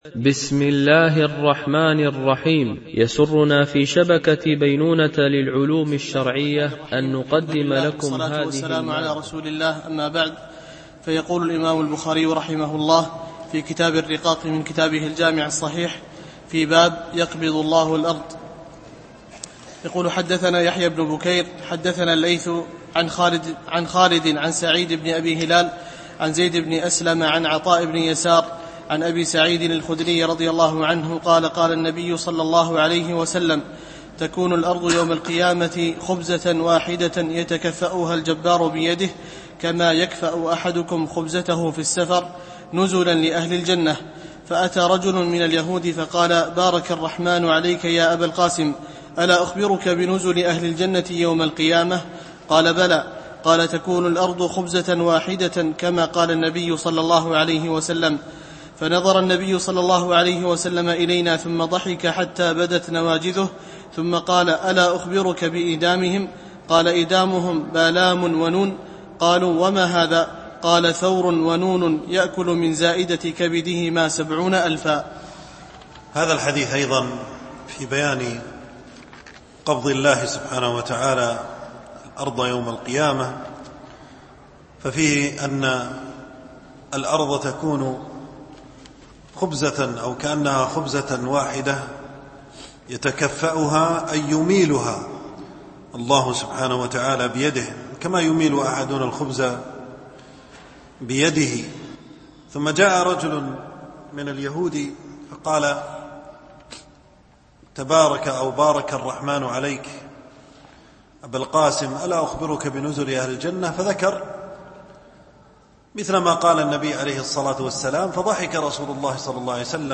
شرح كتاب الرقاق من صحيح البخاري ـ الدرس 7 (الباب 44 - 47)